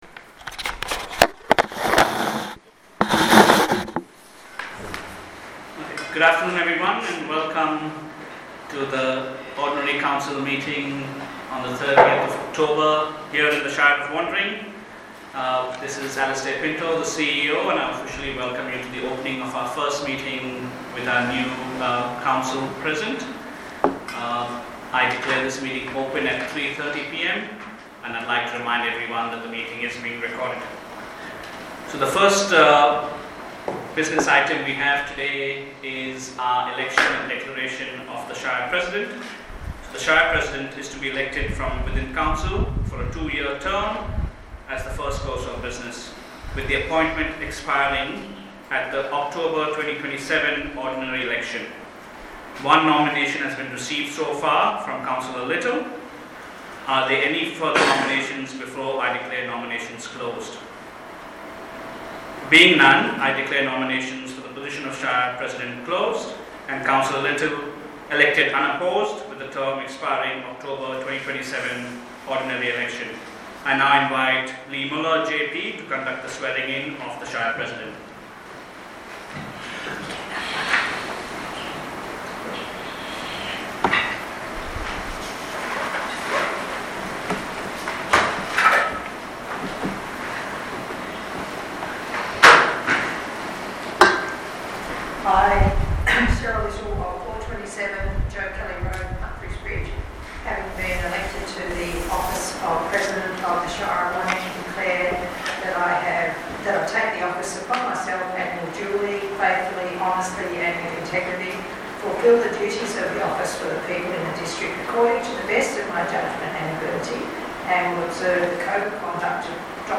october-2025-ordinary-council-meeting-recording.mp3